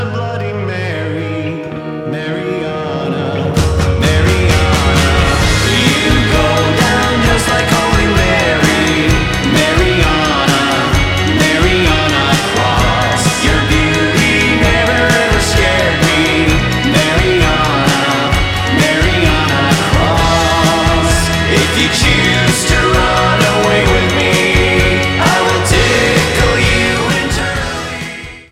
громкие
Pop Rock
alternative
психоделический рок
ретро
psychedelic